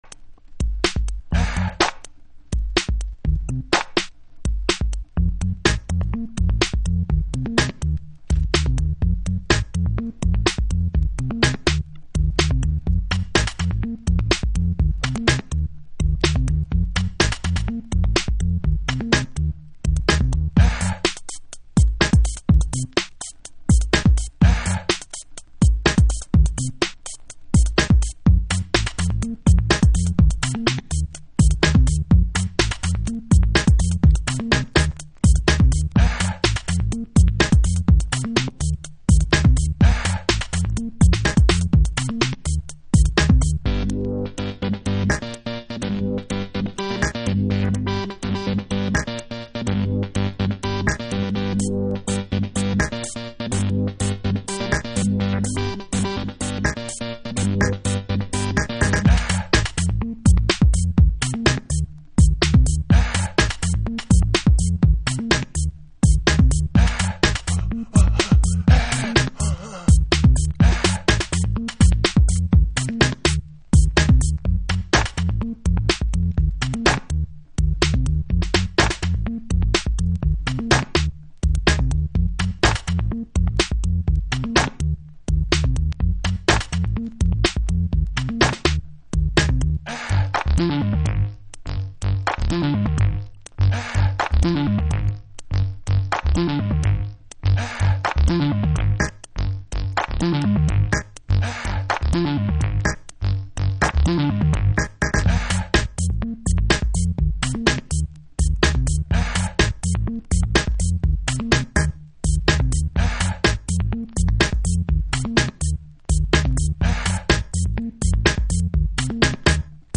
House / Techno
UKベースのデュオによるエレクトロ。